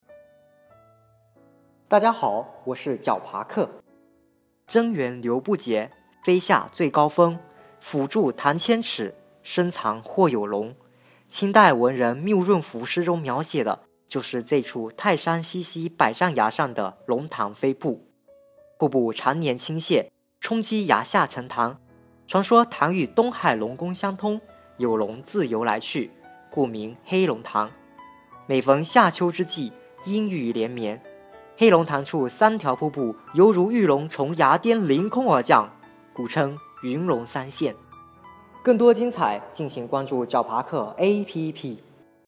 龙潭飞瀑----- 石破天惊 解说词: 黑龙潭是泰山的著名景点，尤以龙潭飞瀑著称。